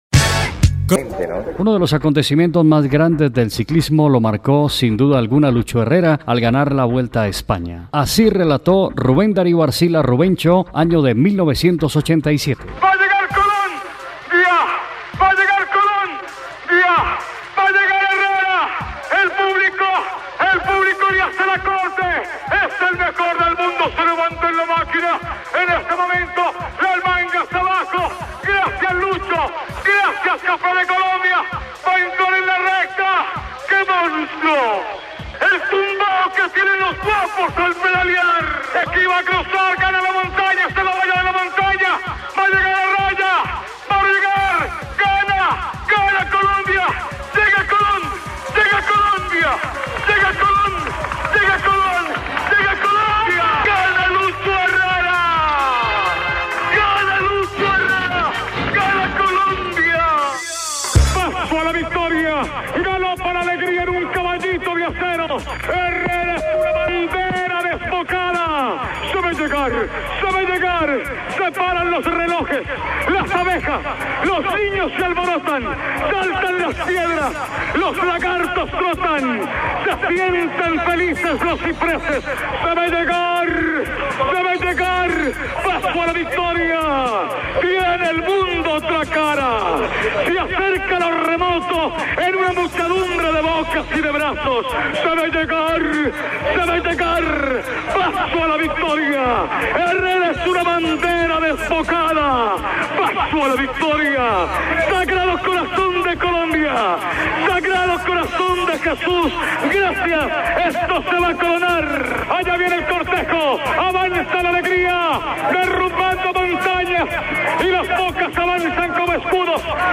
Sin  duda todos hemos escuchado, si no lo recuerdan acá su voz, un timbre similar al de Carlos Arturo Rueda C, narrando en 1987 para la radio: